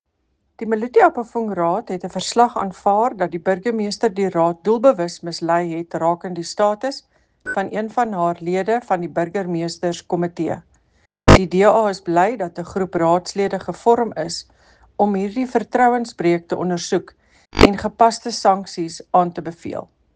Afrikaans soundbite by Cllr Eleanor Quinta and Sesotho soundbite by Cllr Paseka Mokoena